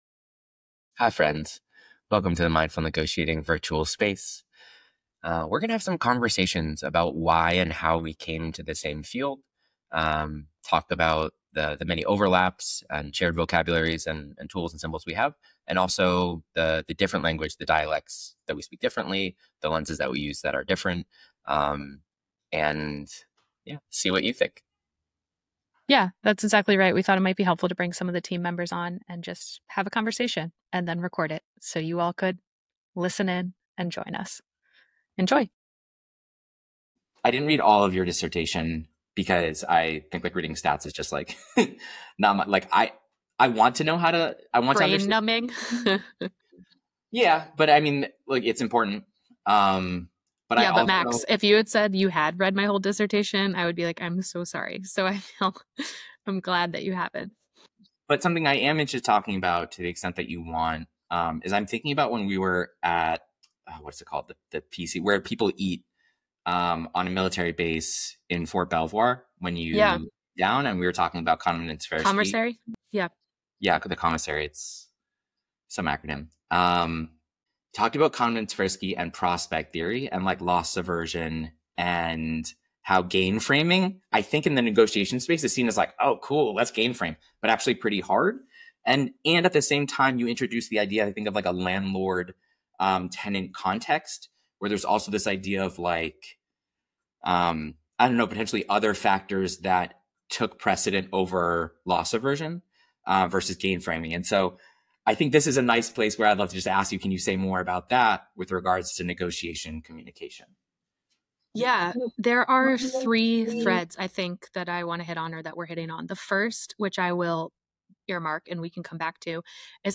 Two negotiation nerds